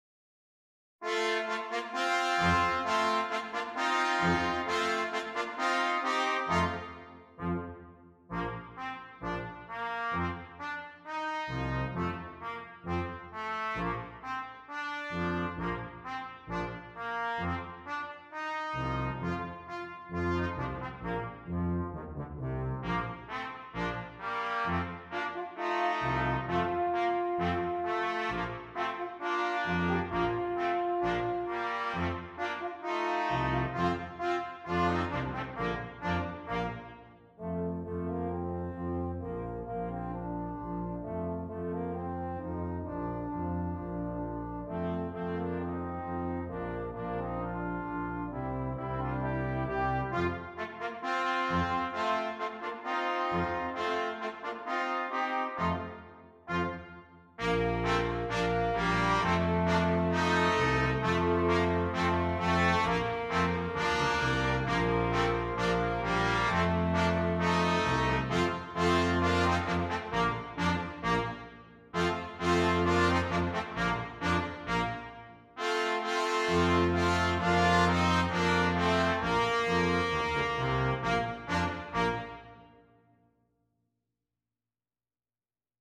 Brass Quintet
processional piece